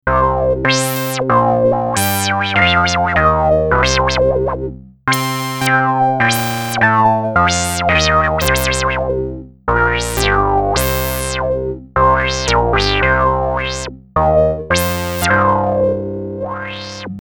SH7 Analog Synthesizer
SOUND nice FM vocal sounds, fast percussive sounds and interesting FM reso filter sounds. duophonic ringmod makes special sounds possible.
Audio Demo - duophonic demo with ringmod
duophonSH7.mp3